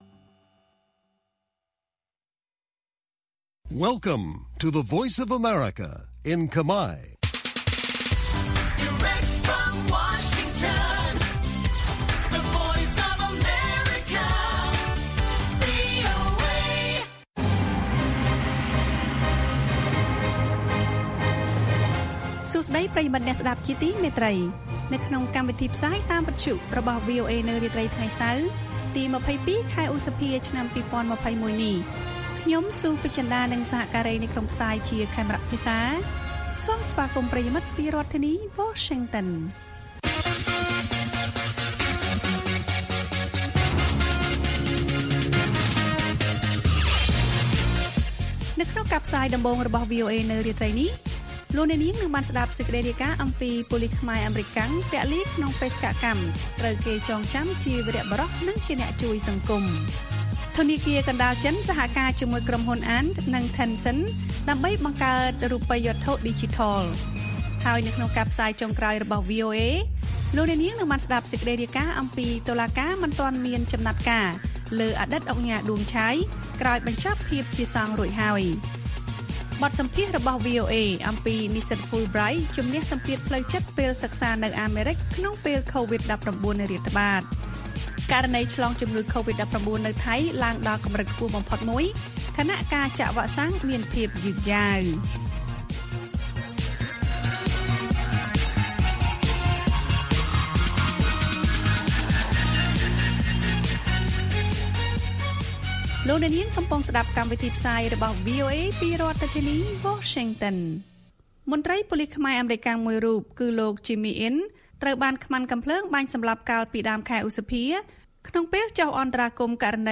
ព័ត៌មានពេលរាត្រី៖ ២២ ឧសភា ២០២១